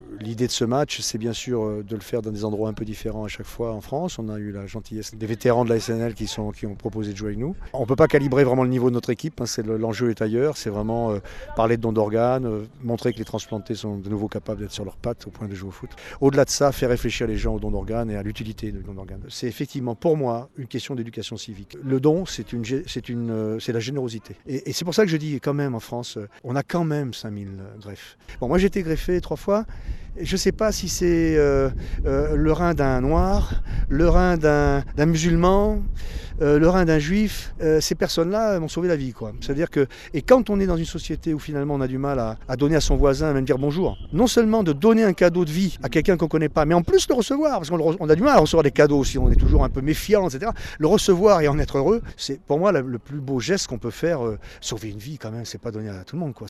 enregistrement radio - match de foot transplants - 15 juin 2013 - itw